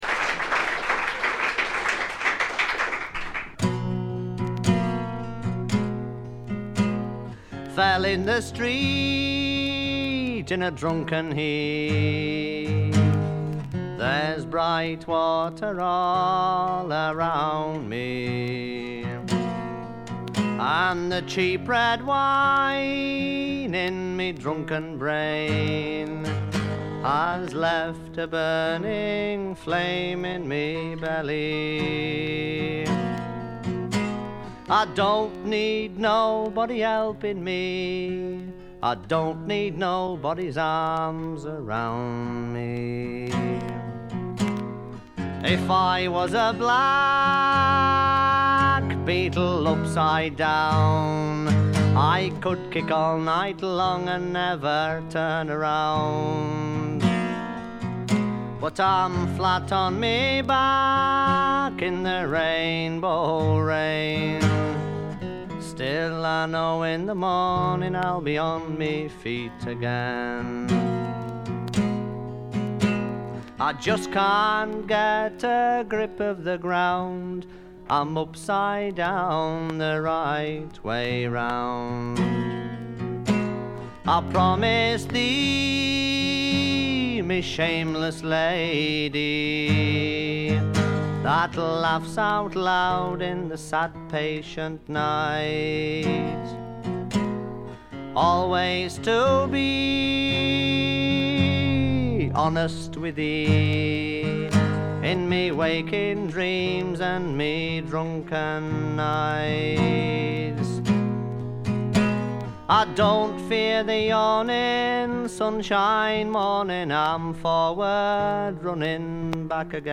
バックグラウンドノイズ、チリプチはそこそこ出ますが鑑賞を妨げるほどのノイズはありません。
自身のギターの弾き語りで全13曲。
試聴曲は現品からの取り込み音源です。
guitar, vocals